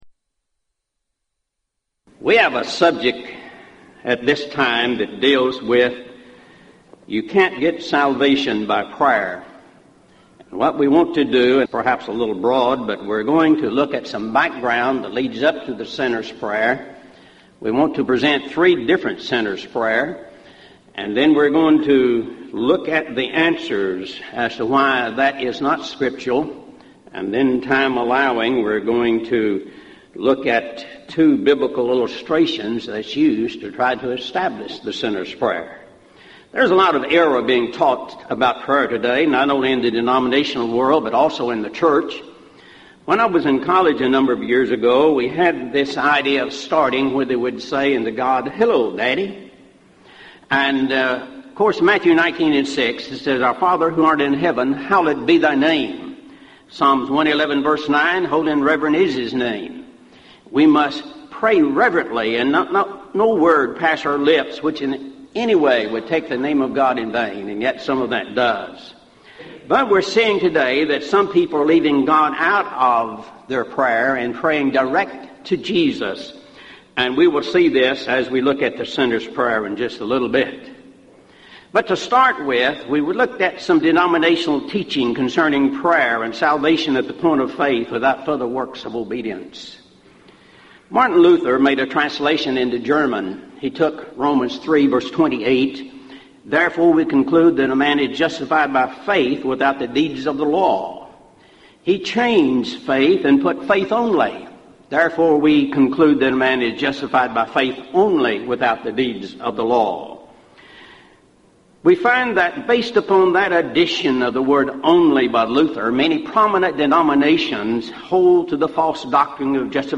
Event: 1998 Gulf Coast Lectures Theme/Title: Prayer and Providence
this lecture